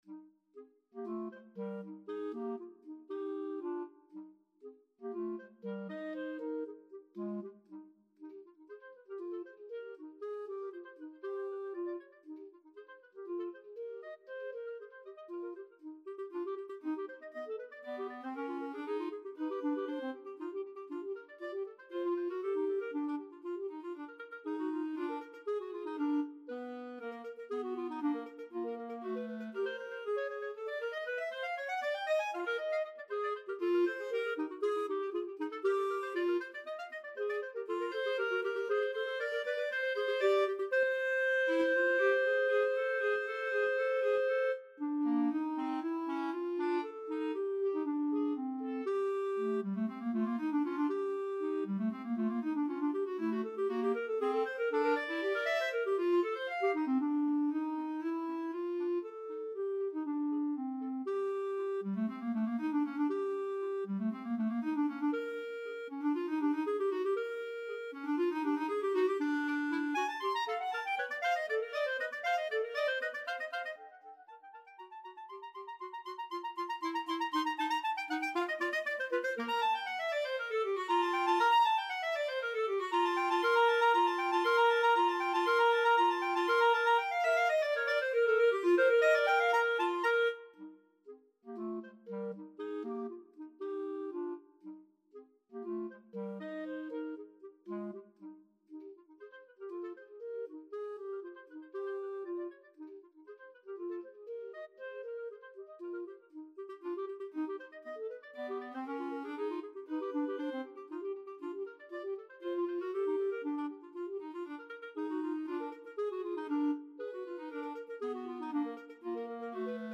Free Sheet music for Clarinet Duet
Eb major (Sounding Pitch) F major (Clarinet in Bb) (View more Eb major Music for Clarinet Duet )
2/4 (View more 2/4 Music)
Allegro giusto = 118 (View more music marked Allegro)
Classical (View more Classical Clarinet Duet Music)